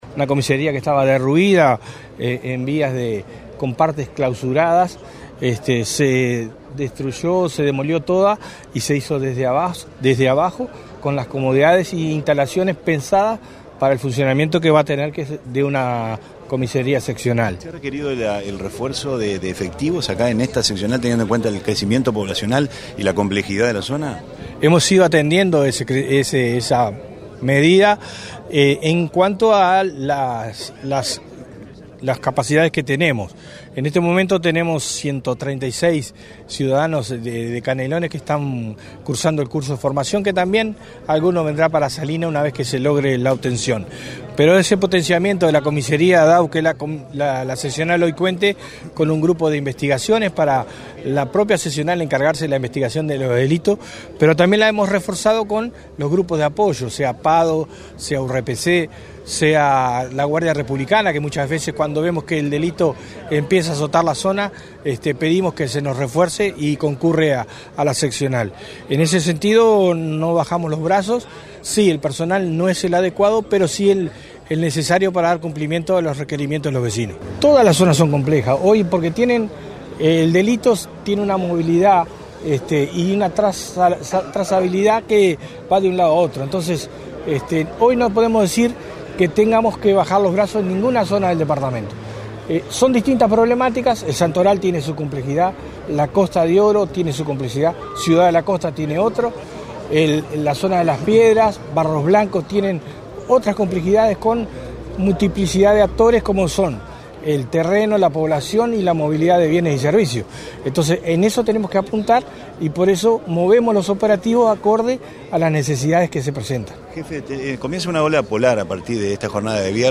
Escuchar a Trezza: